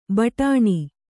♪ baṭāṇi